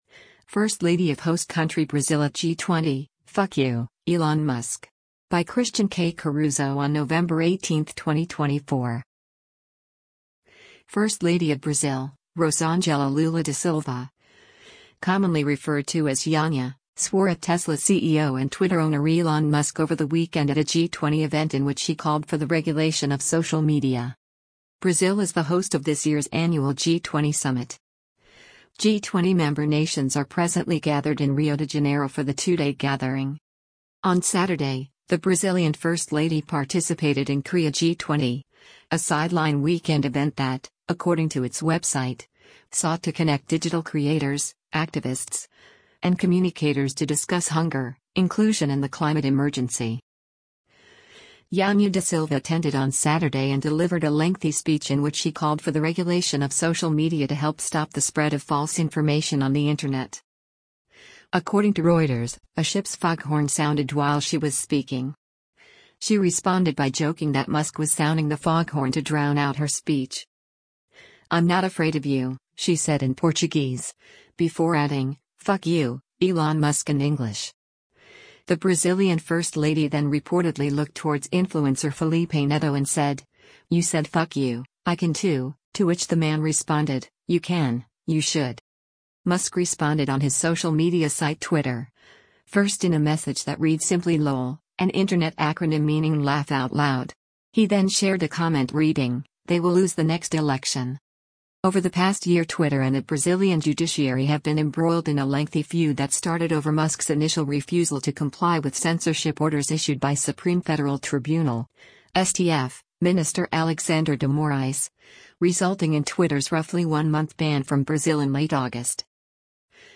On Saturday, the Brazilian first lady participated in Cria G20, a sideline weekend event that, according to its website, sought to connect digital creators, activists, and communicators to “discuss hunger, inclusion and the climate emergency.”
Janja da Silva attended on Saturday and delivered a lengthy speech in which she called for the regulation of social media to help stop the spread of “false information” on the internet.
According to Reuters, a ship’s foghorn sounded while she was speaking.